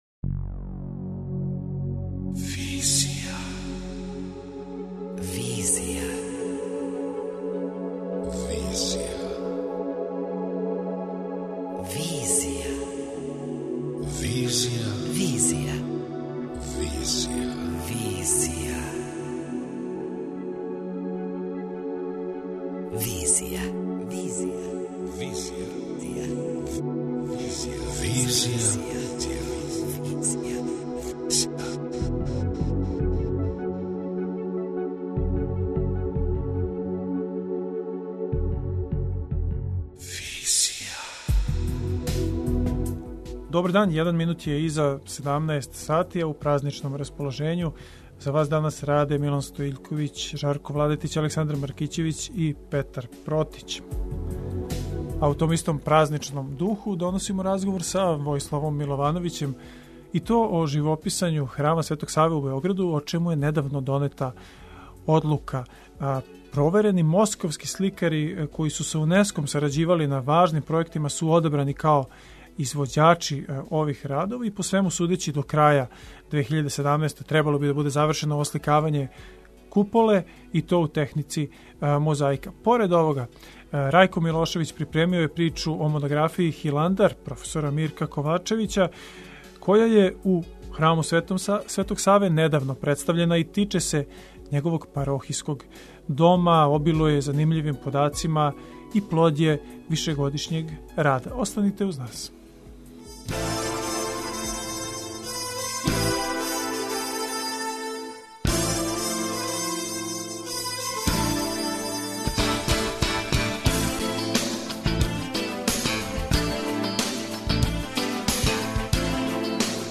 преузми : 28.07 MB Визија Autor: Београд 202 Социо-културолошки магазин, који прати савремене друштвене феномене.